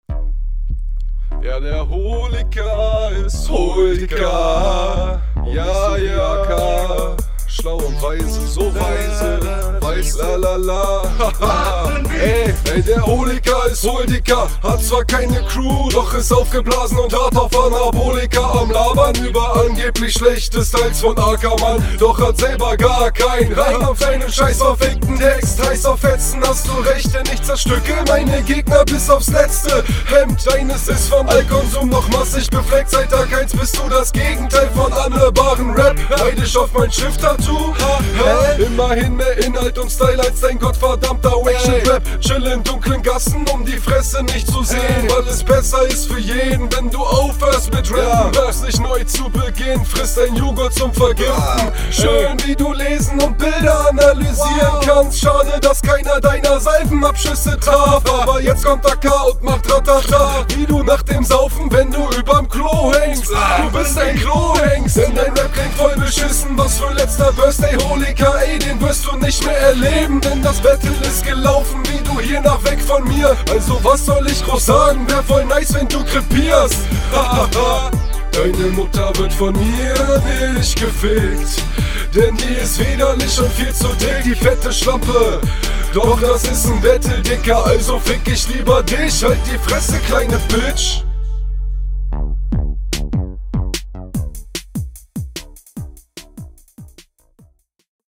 Mir gefällt dein Stimmeinsatz besser als beim Gegner.